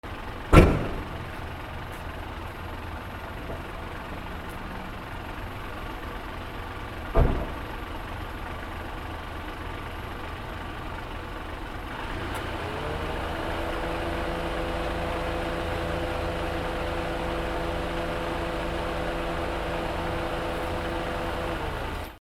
/ E｜乗り物 / E-10 ｜自動車
車 ドアなど